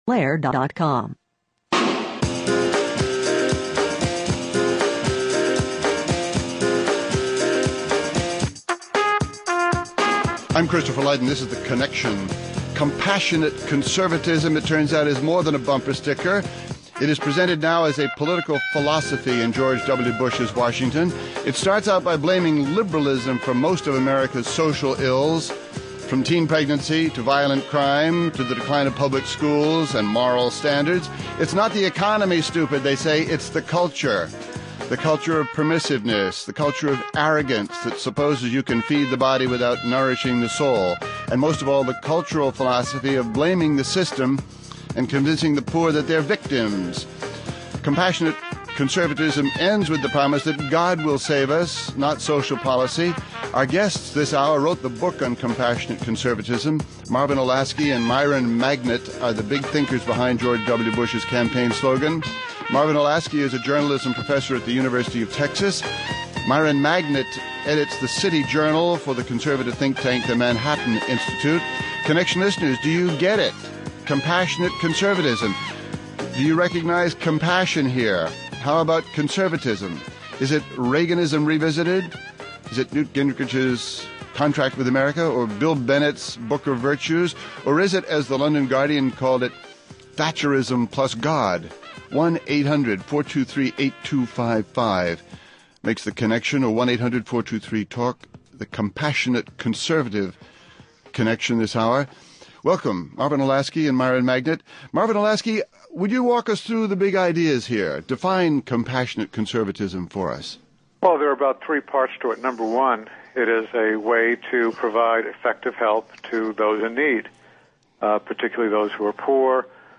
(Hosted by Christopher Lydon)